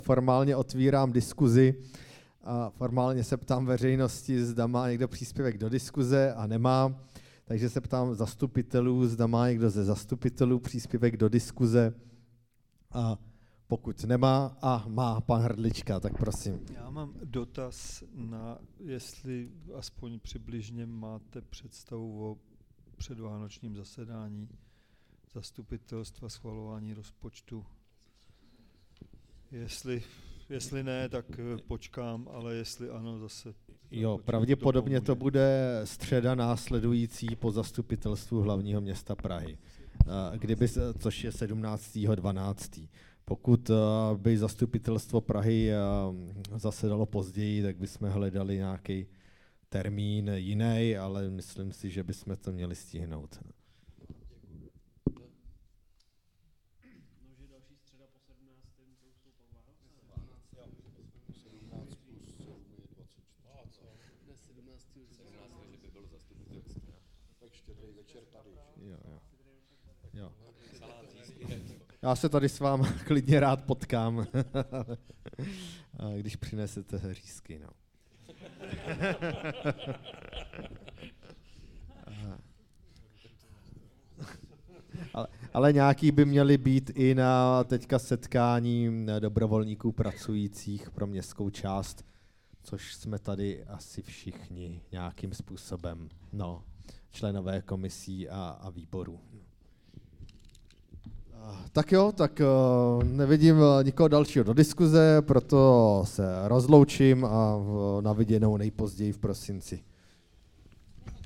22. veřejné zasedání ZMČ